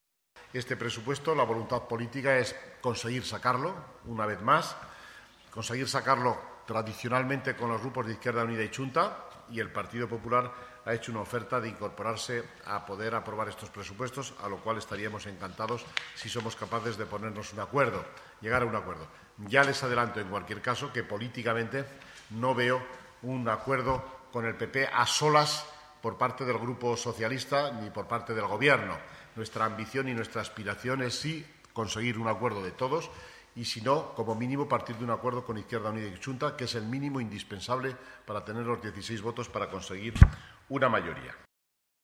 Finalmente, el vicxealcalde y consejero de Economía y Hacienda, ha valorado así las futuras negociaciones para aprobar el presupuesto: